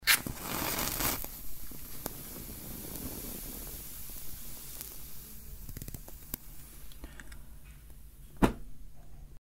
Звуки спички
Звук горящей спички и задувание